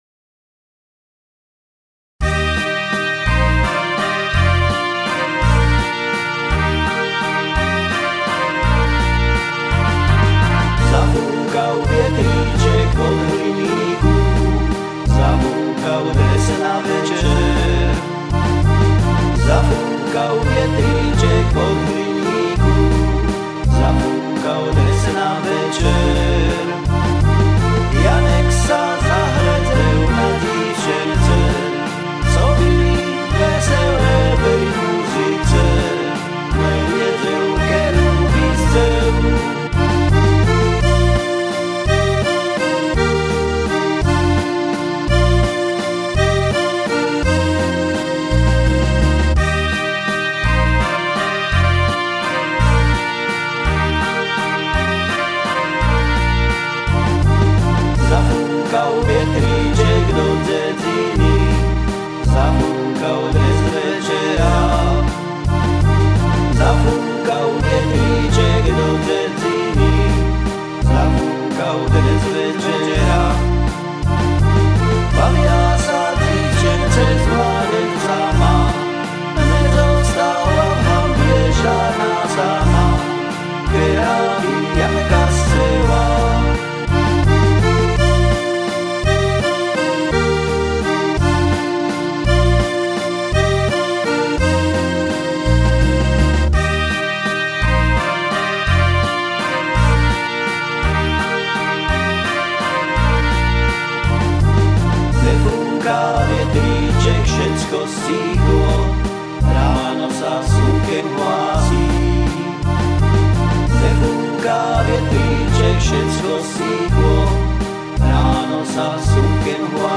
zafukau-vjetricek-rychlejsie.mp3